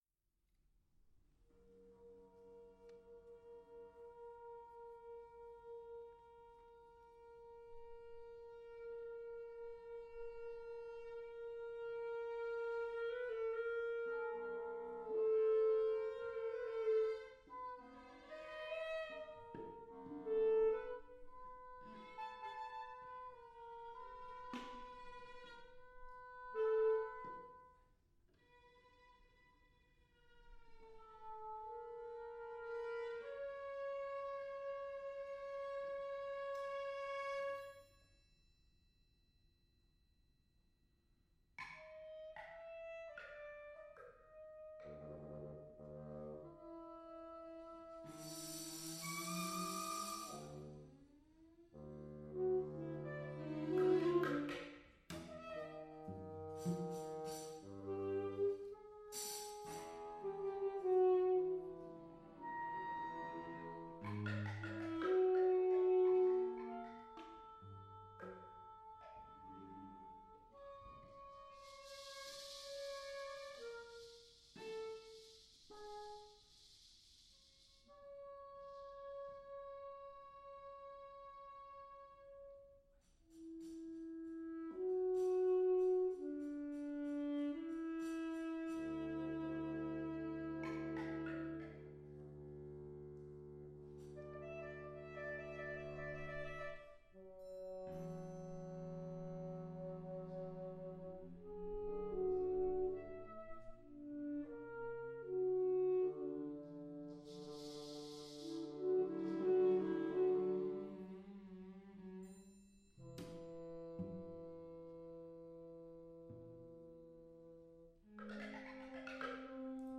Alto saxophone, bassoon, cello, and percussion